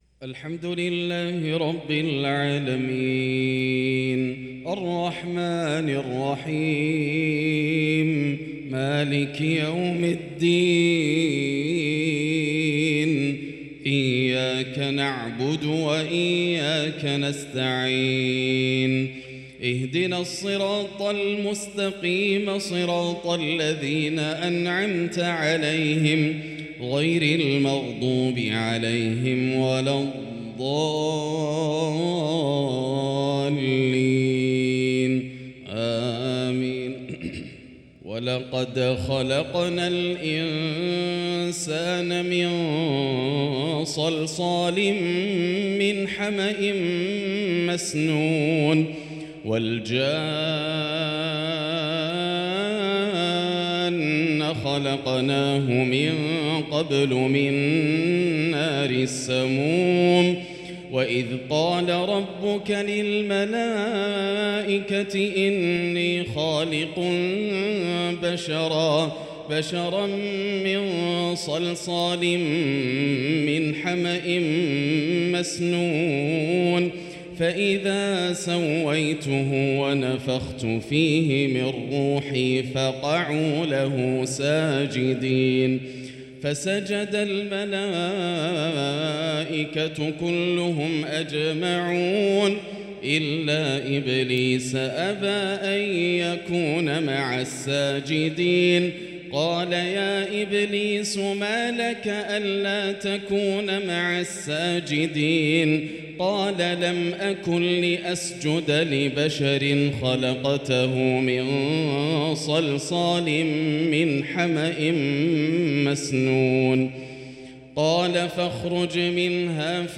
صـلاة العشاء الشيخان عـبـد الرحمن السديس وعلي الحذيفي قرأ الشيخ عـبـد الرحمن السديس آواخر ســورتي الحجر والنحل بينما قرأ الشيخ علي الحذيفي من ســورة الأحزاب